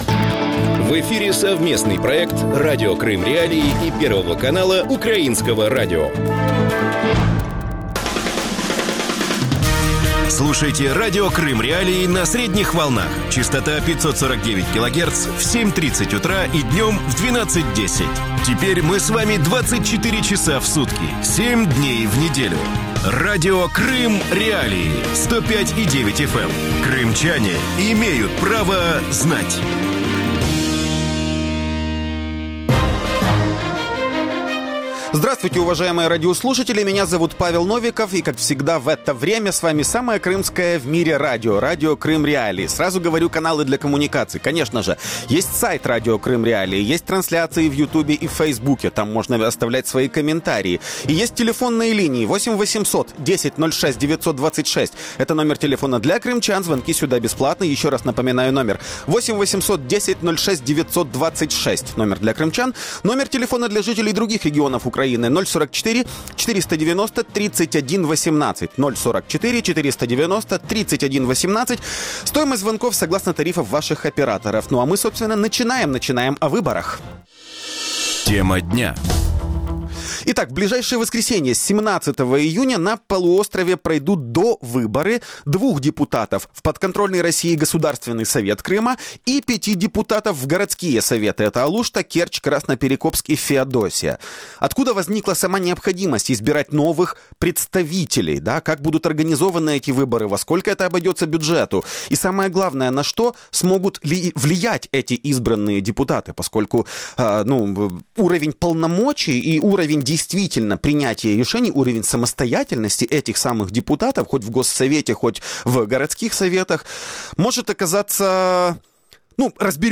российский политэмигрант
украинский политолог